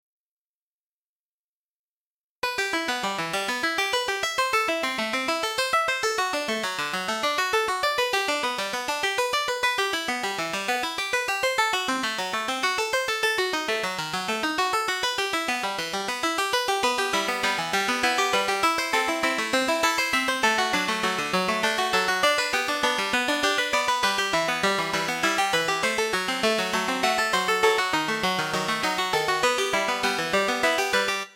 Опять классический закос
Arpeggios.mp3